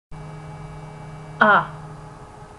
Latin short a has the sound in...,
idea, or the first a in aha (a quick ah)
short_a.wma